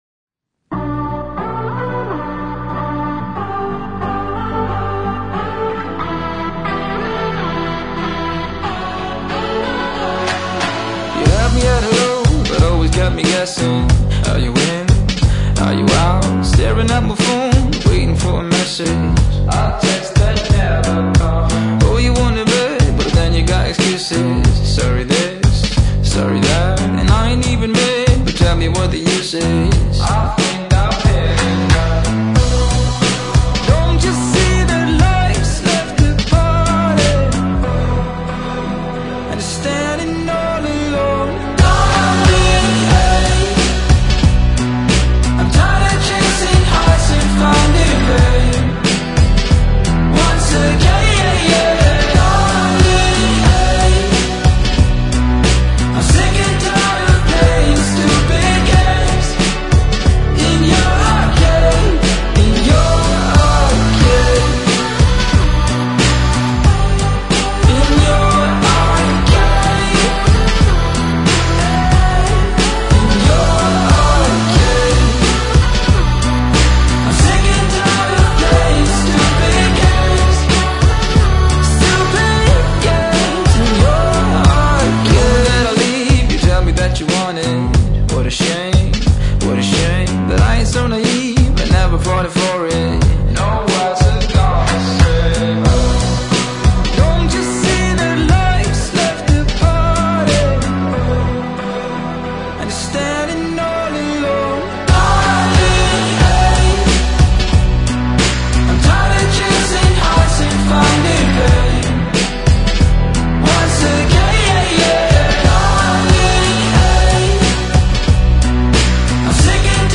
El cantante y compositor